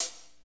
HAT F S CH0D.wav